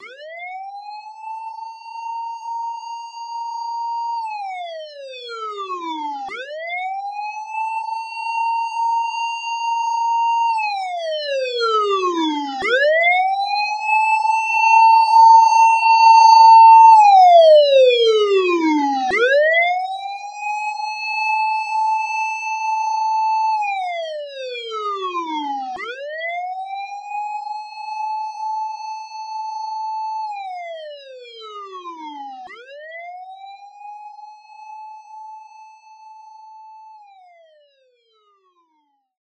消防車が通過する音の着信音は、消防車のサイレン音が左から右へ移動する効果音です。